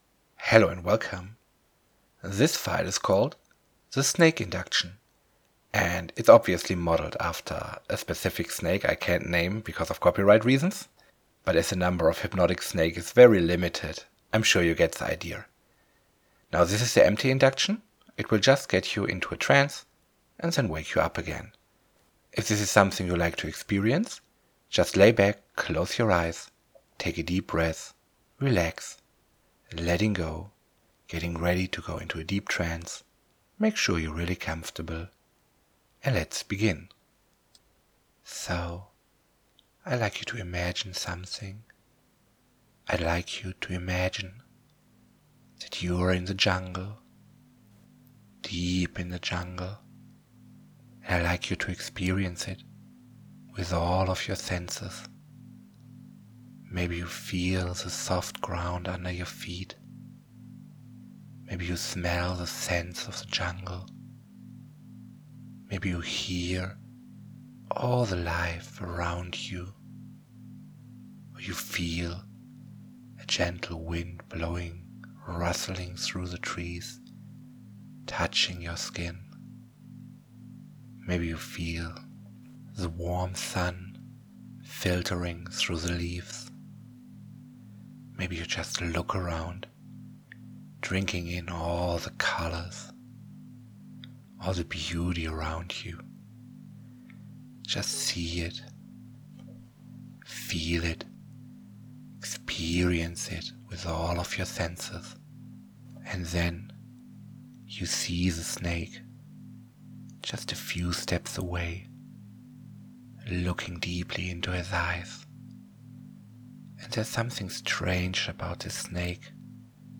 This is the empty version of the Snake Induction without any effects.